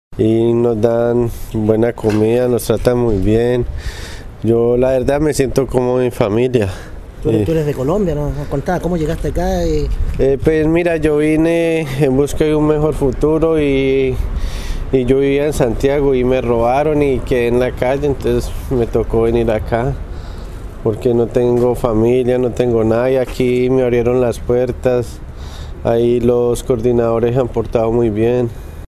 COLOMBIANO-X-ALBERGUE-1.mp3